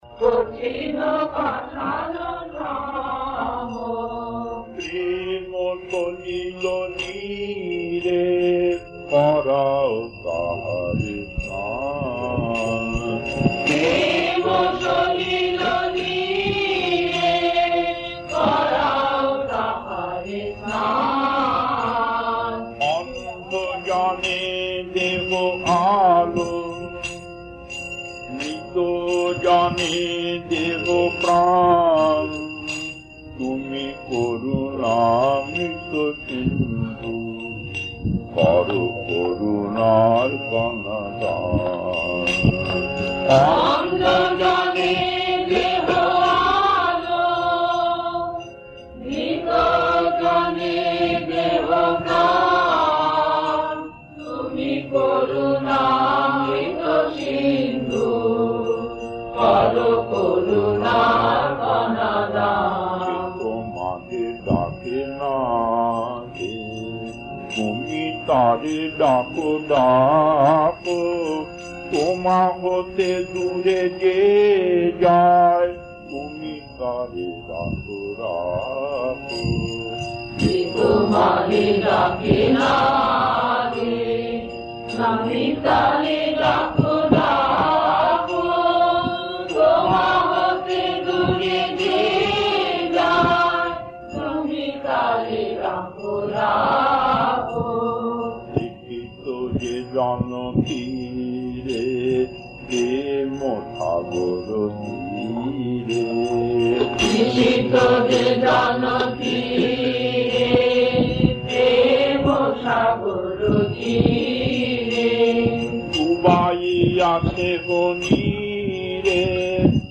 Kirtan B4-2 Puri 1993, 36 minutes 1.